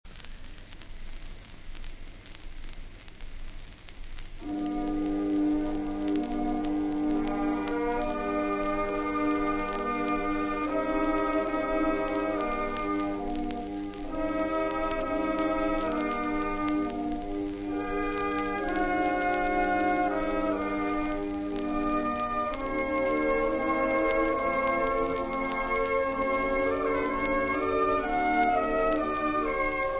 Personaggi e interpreti: Tenore ; Caruso, Enrico